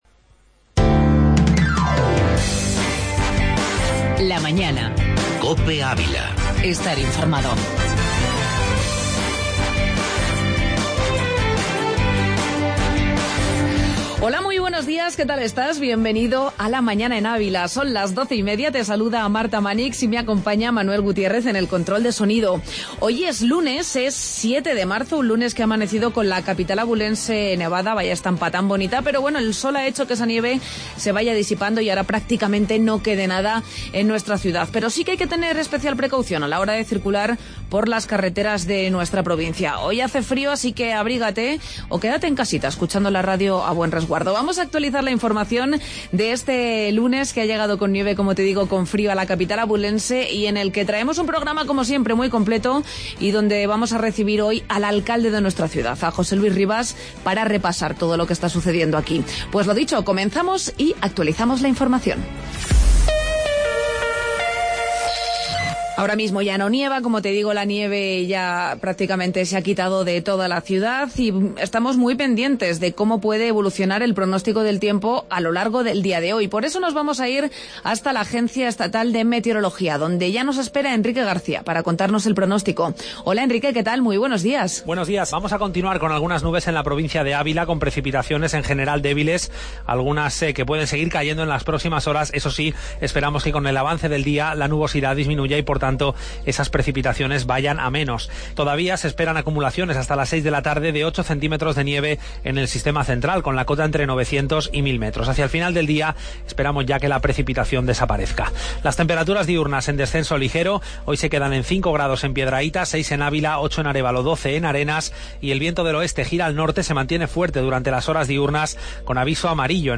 AUDIO: Entrevista sumilleres de Ávila y Espacio ESSE